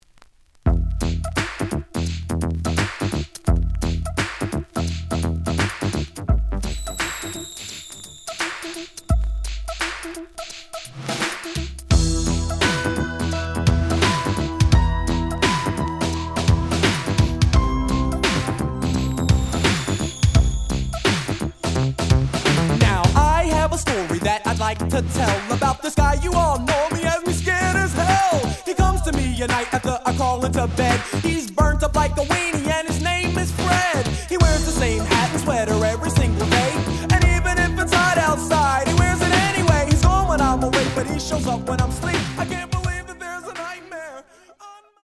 The audio sample is recorded from the actual item.
●Format: 7 inch
●Genre: Hip Hop / R&B